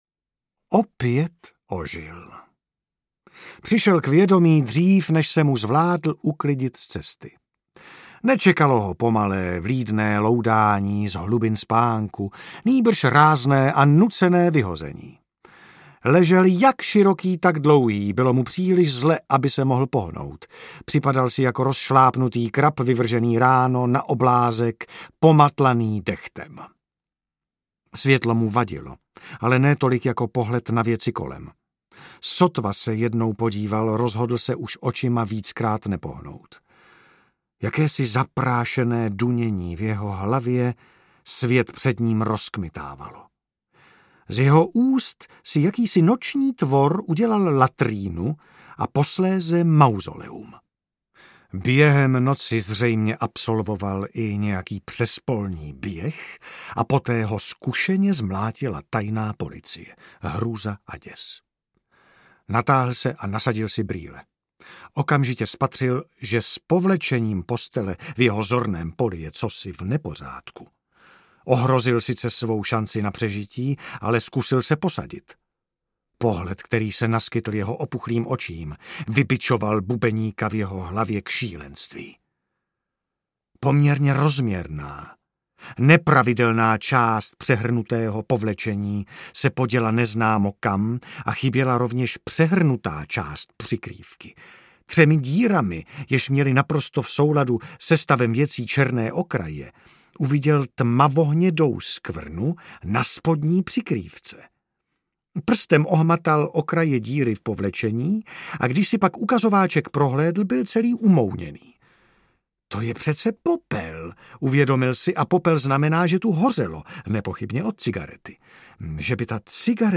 Šťastný Jim audiokniha
Ukázka z knihy
stastny-jim-audiokniha